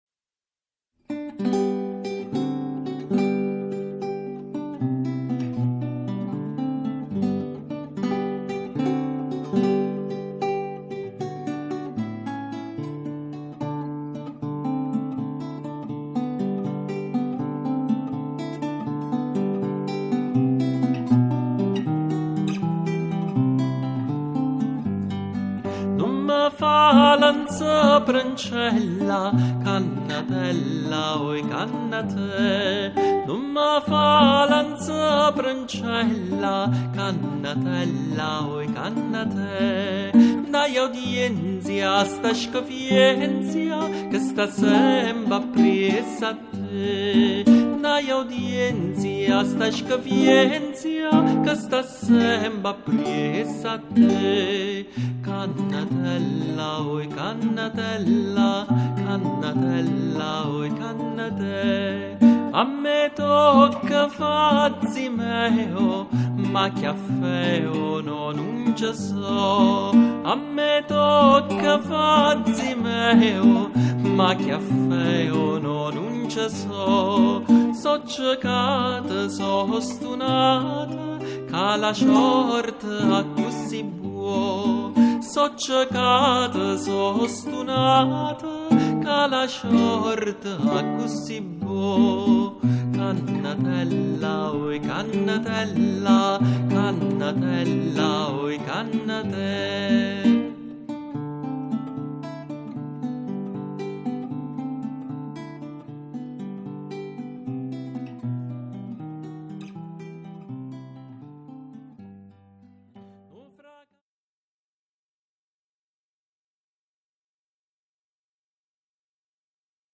La canzone napoletana dal 1799 al 1887